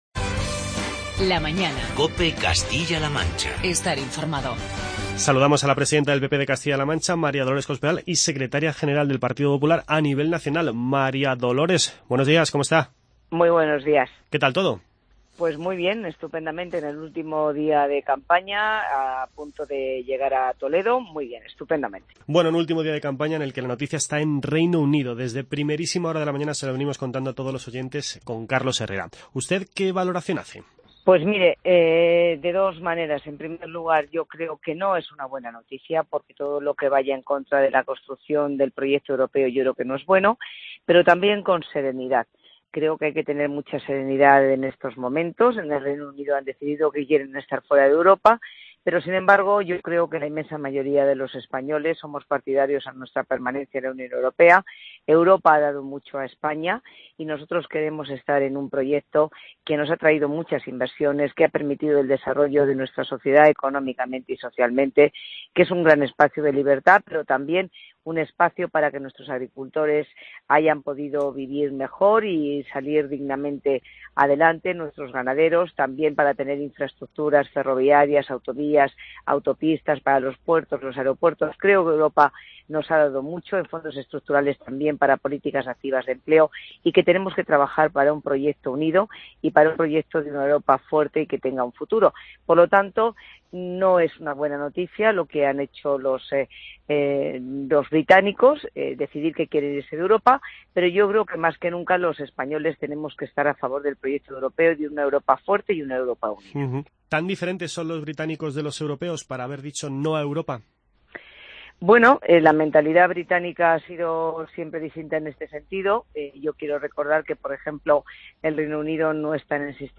Escuche la entrevista con María Dolores Cospedal, candidata del Partido Popular al Congreso de los Diputados por la provincia de Toledo, presidenta del PP de Castilla-La Mancha y secretaria general del Partido Popular.